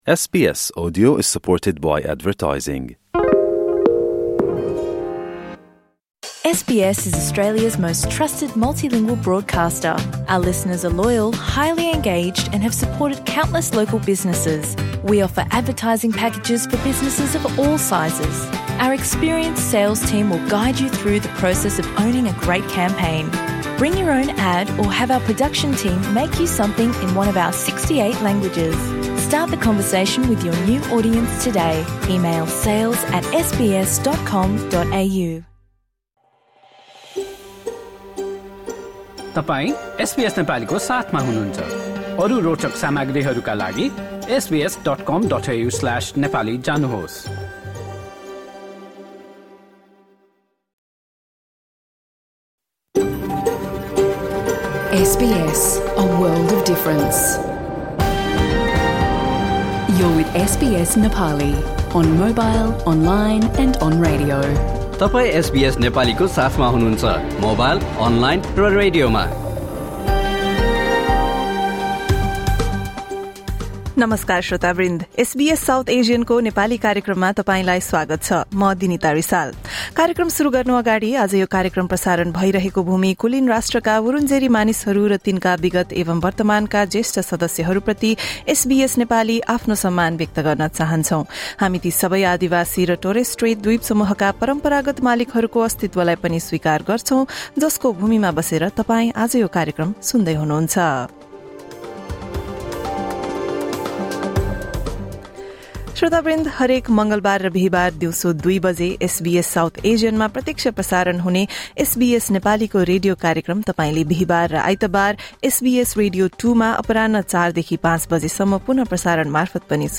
Rocket, cricket and motorsports: SBS Nepali’s radio broadcast from Thursday, 16 October 2025